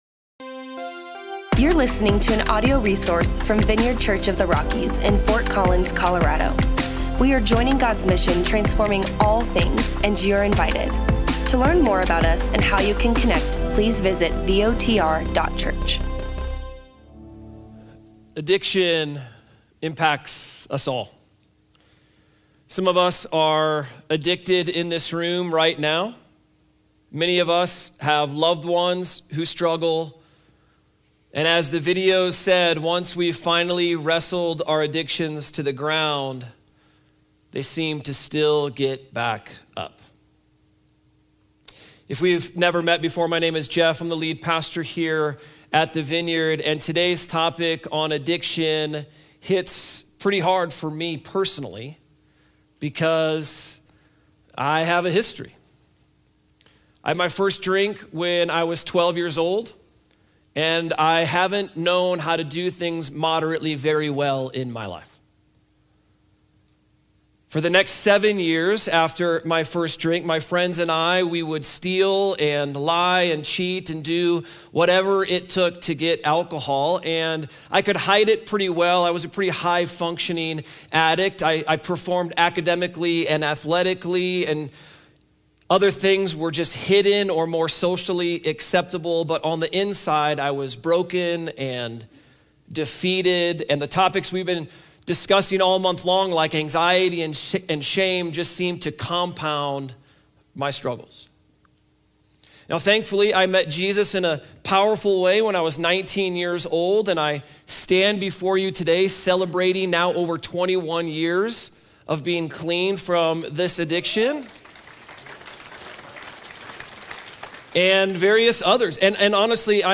Join us for a unique service at the Vineyard.